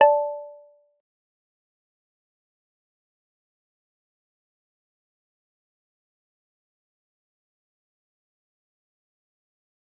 G_Kalimba-D6-mf.wav